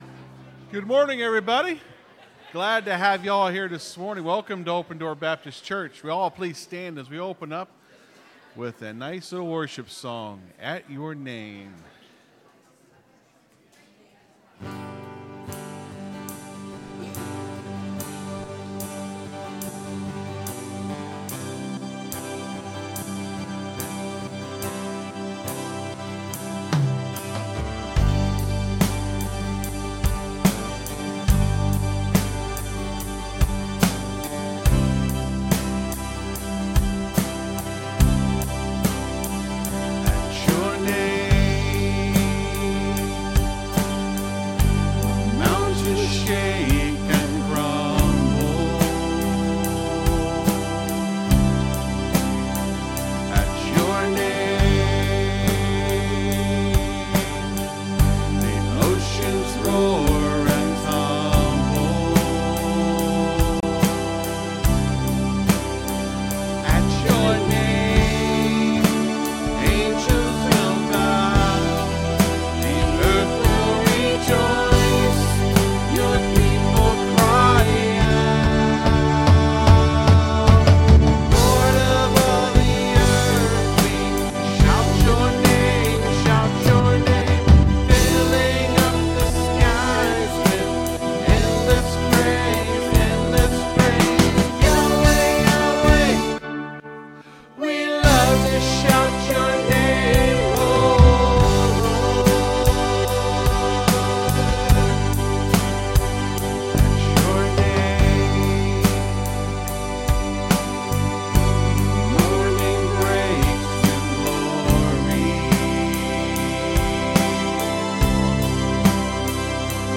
(Sermon starts at 24:40 in the recording)